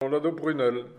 Localisation Xanton-Chassenon
Catégorie Locution